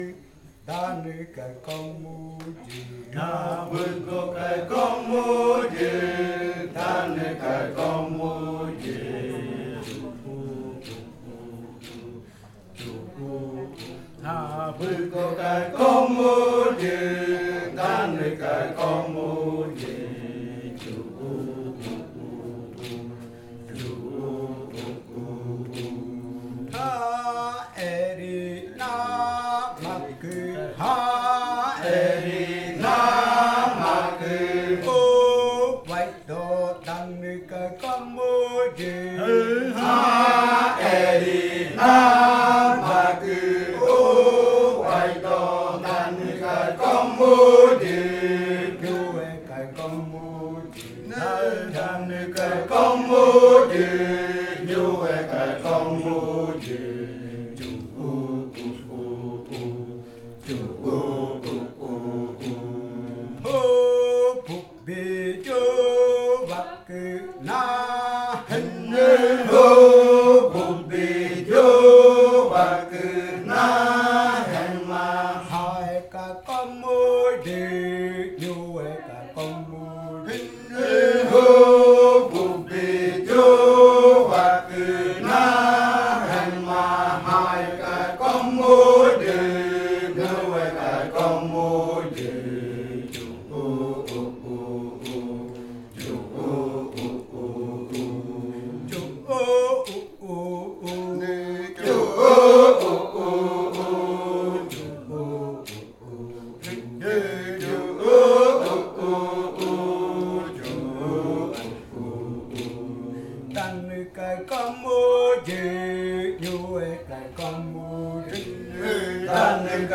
Canto de saltar de la variante jaiokɨ
Leticia, Amazonas
con el grupo de cantores sentado en Nokaido.
with the group of singers seated in Nokaido. This song is part of the collection of songs from the Yuakɨ Murui-Muina ritual (fruit ritual) of the Murui people, a collection that was compiled by the Kaɨ Komuiya Uai Dance Group with support from UNAL, Amazonia campus.